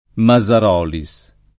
[ ma @ ar 0 li S ]